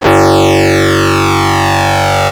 ihob/Assets/Extensions/RetroGamesSoundFX/Hum/Hum02.wav at master
Hum02.wav